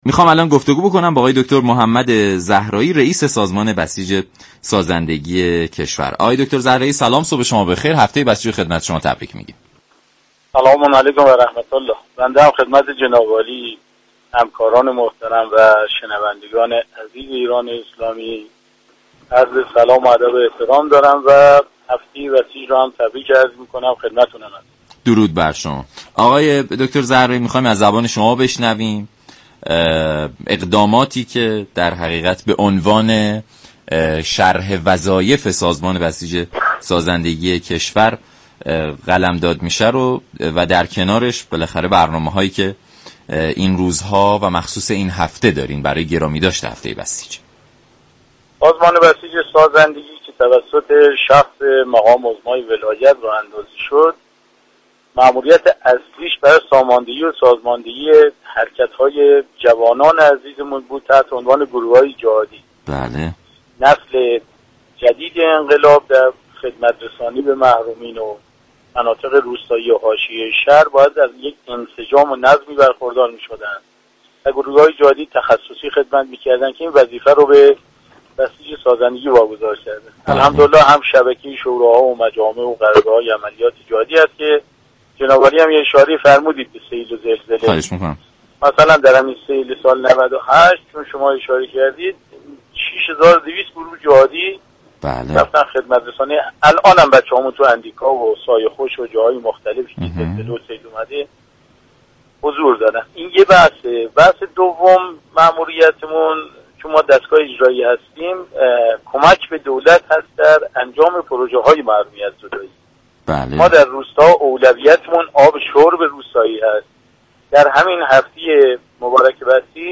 به گزارش شبكه رادیویی ایران، «محمدزهرایی» رییس سازمان بسیج سازندگی در برنامه «سلام صبح بخیر» رادیو ایران به شرح اقدامات سازمان بسیج سازندگی پرداخت و گفت: ماموریت اصلی سازمان، ساماندهی و سازمان دهی حركت جوانان ایران است، جوانانی كه وظیفه خدمات رسانی به محرومان، مناطق روستایی و حاشیه شهر را بر عهده دارند.